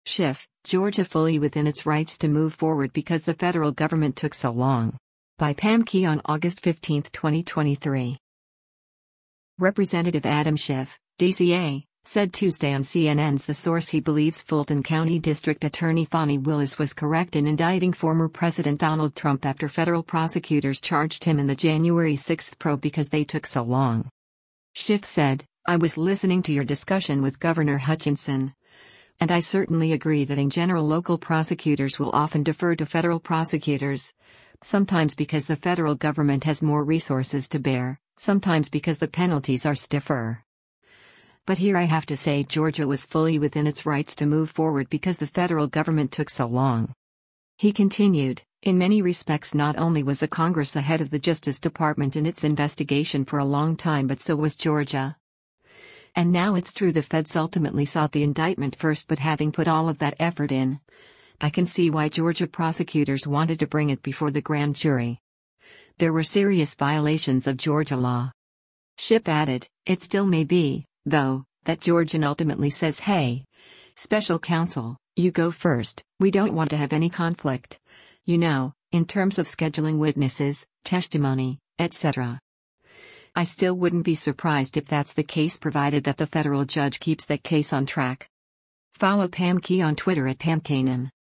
Representative Adam Schiff (D-CA) said Tuesday on CNN’s “The Source” he believes Fulton County District Attorney Fani Willis was correct in indicting former President Donald Trump after federal prosecutors charged him in the January 6 probe because they took “so long.”